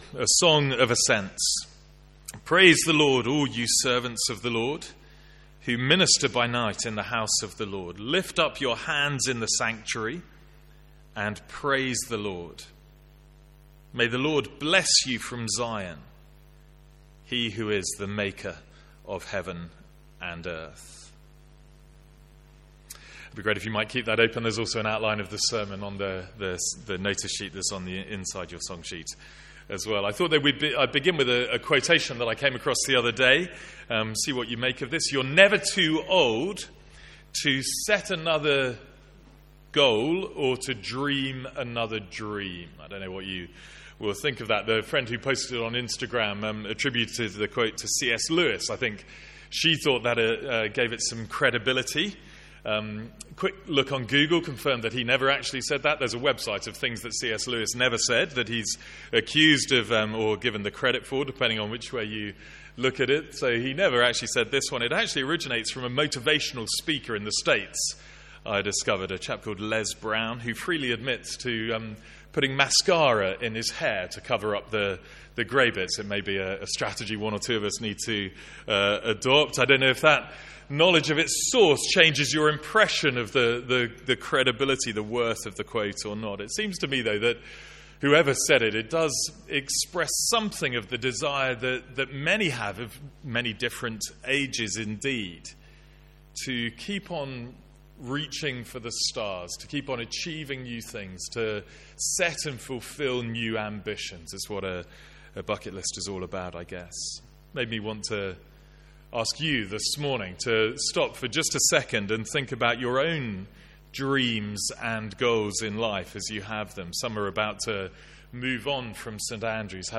Sermons | St Andrews Free Church
From the Sunday morning series in the Psalms.